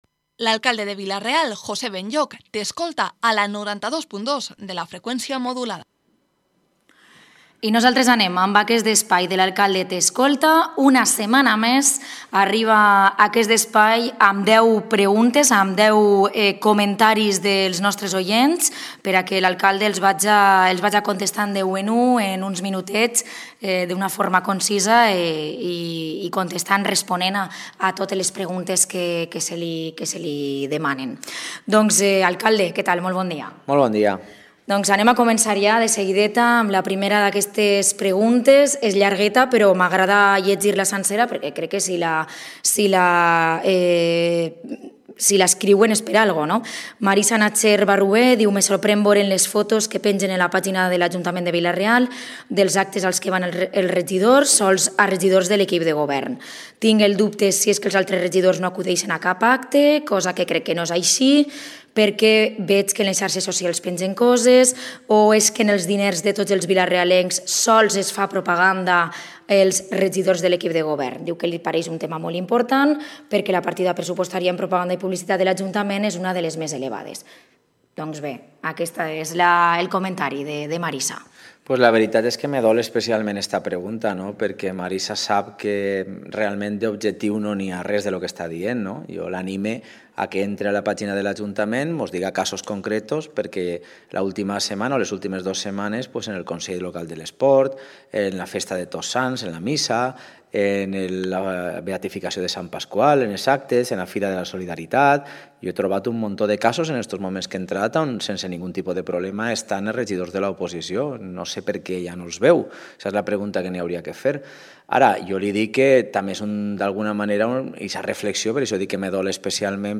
Espacio semanal dedicado a preguntas y respuestas al alcalde de Vila-real. Los vecinos plantean sus inquietudes a José Benlloch quien, cada semana, responderá en directo a 10 cuestiones de nuestros oyentes.